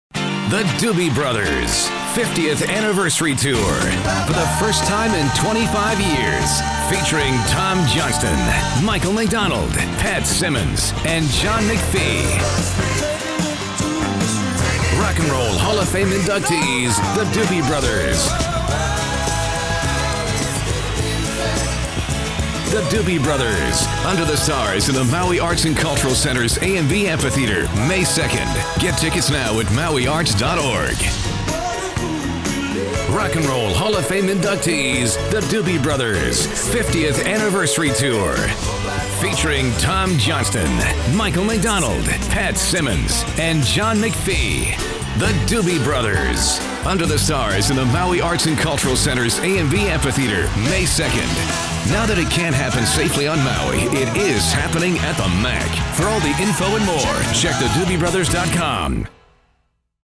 For over five decades, The Doobie Brothers have delivered mind-blowing, roots based, harmony-laden, guitar-driven rock and roll – all of which culminated in an induction into the 2020 Rock & Roll Hall of Fame.